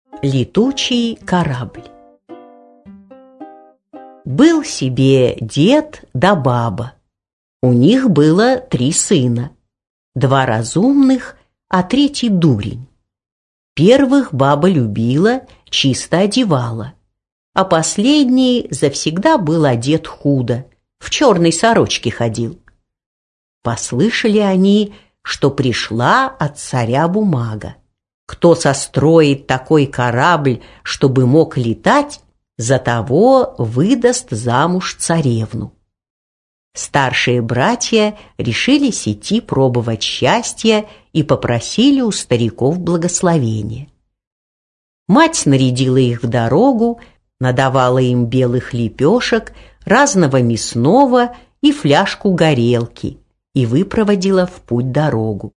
Аудиокнига Летучий корабль | Библиотека аудиокниг